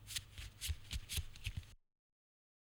少し効果音を録音しました。